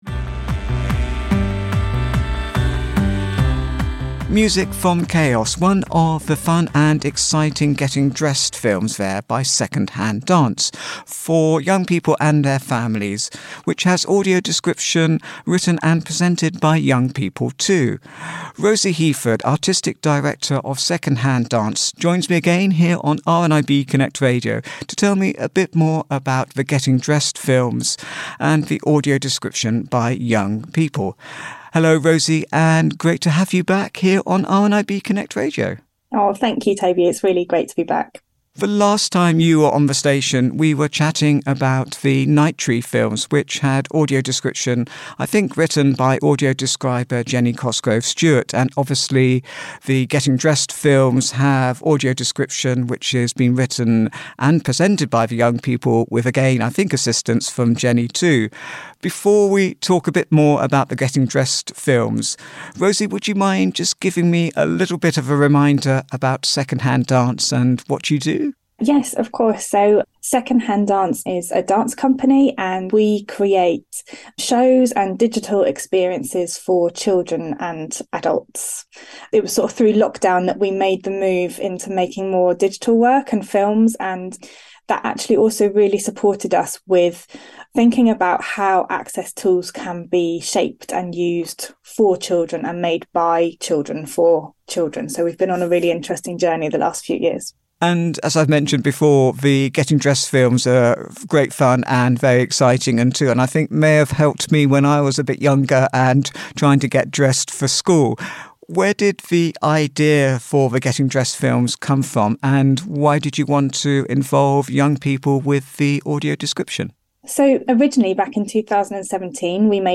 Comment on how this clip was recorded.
The interview also included a short clip from Chaos one of the Getting Dressed films with fantastic description by one of the young people.